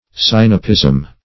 Sinapism \Sin"a*pism\, n. [L. sinapismus, Gr.